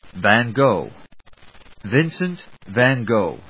/vængóʊ(米国英語), vængˈɔf(英国英語)/